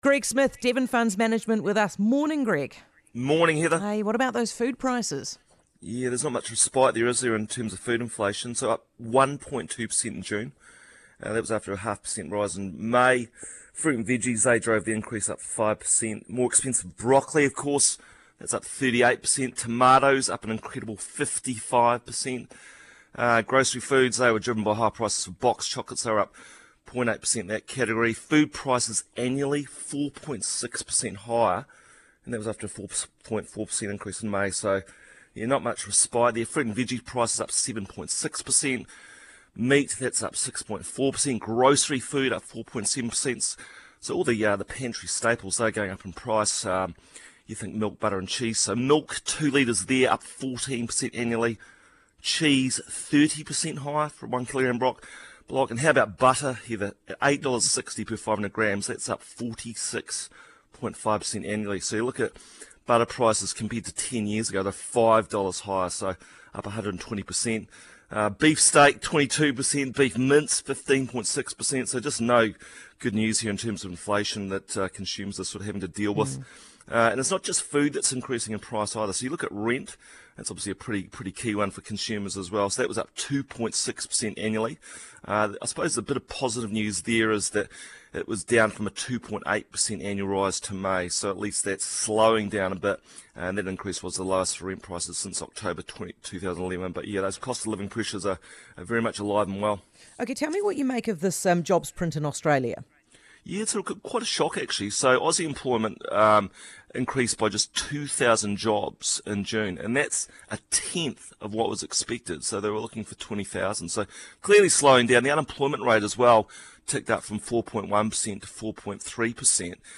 market update